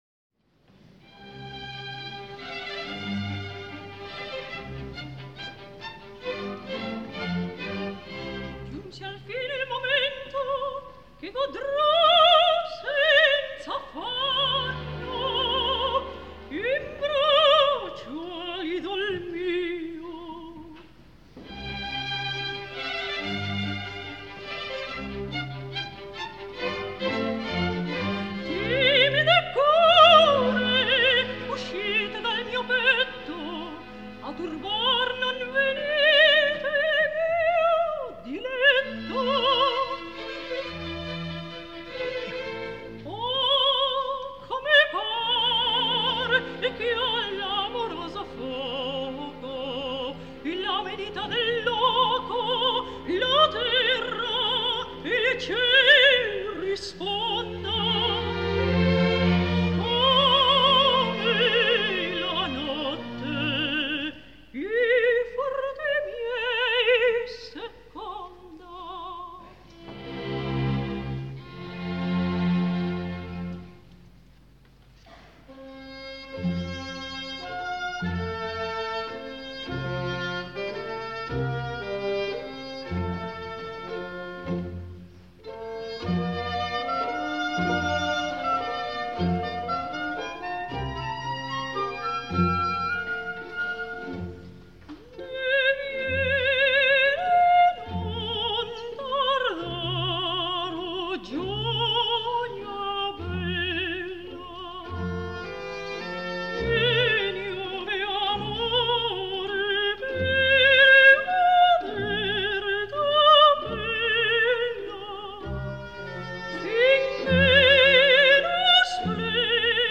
Soprano. W.A. Mozart: Deh vieni, non tardar (Susanna’s aria, Act IV).
Orchestre de la Suisse Romande.